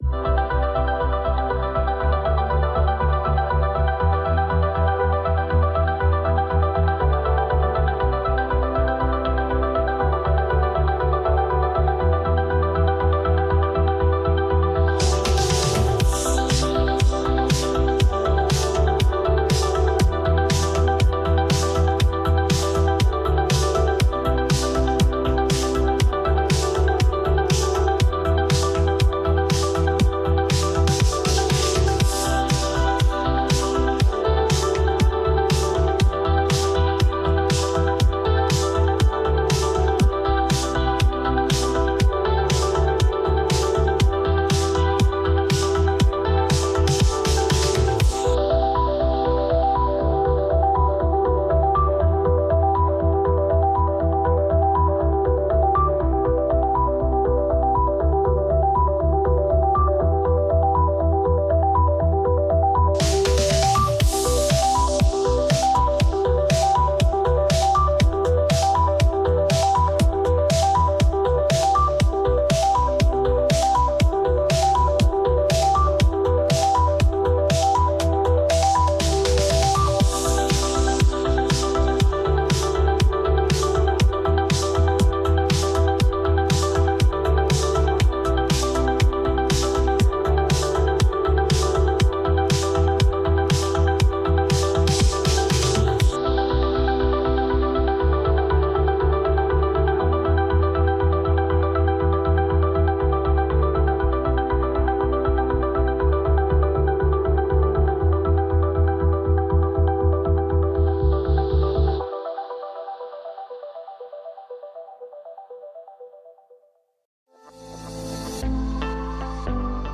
BoardingMusic[2].ogg